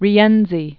(rē-ĕnzē) or Ri·en·zo (-zō), Cola di 1313?-1354.